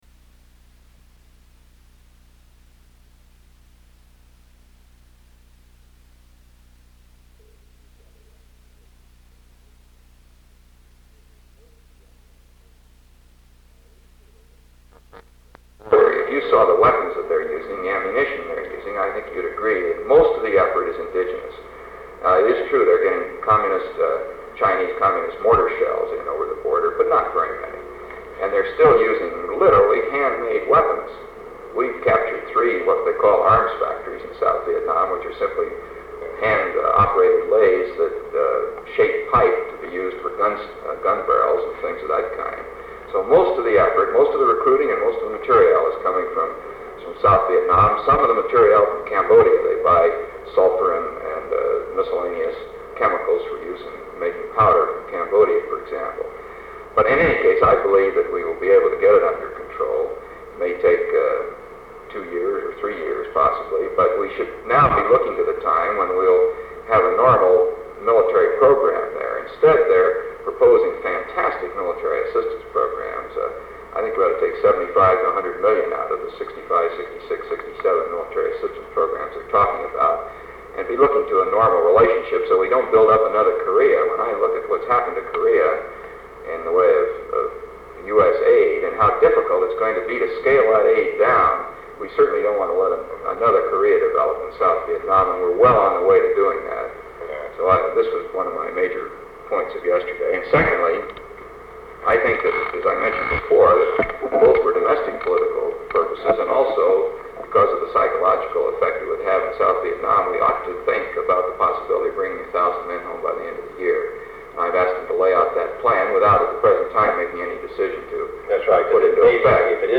Sound recording of an undated meeting, possibly held on May 7, 1963, between President John F. Kennedy and Secretary of Defense Robert S. McNamara. They discuss various issues concerning the Department of Defense (DOD), including the military situation in Vietnam, the Congressional budget fight on military spending in Vietnam and personnel issues in DOD, specifically the nomination of a new Deputy Secretary of Defense.